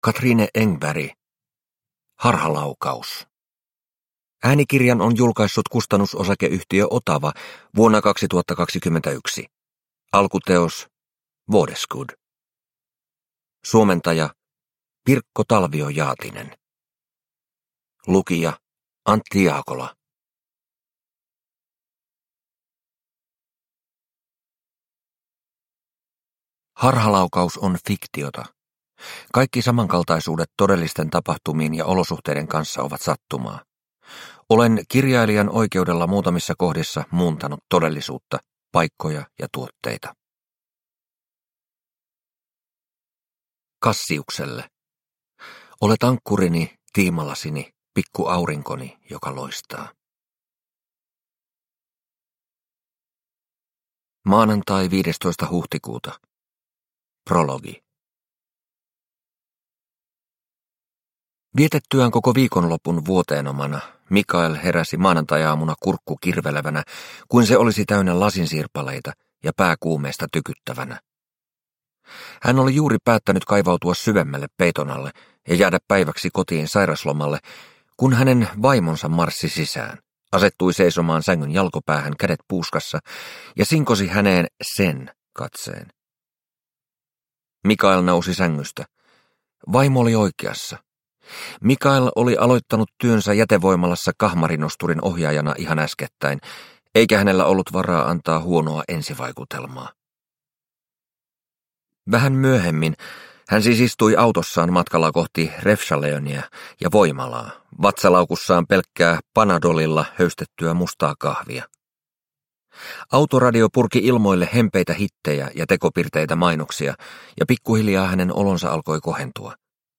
Harhalaukaus – Ljudbok – Laddas ner